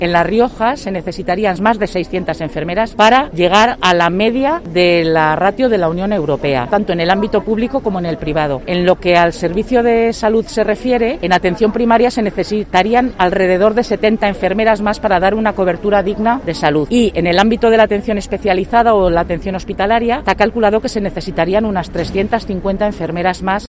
Así protestan los profesionales de la Enfermería y la Fisioterapia de La Rioja